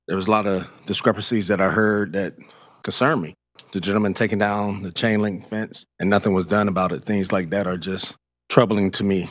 Councilmember Vic Ledbetter, a former captain with Kalamazoo Public Safety, asked for the police reports to see why this hasn’t been handled by law enforcement.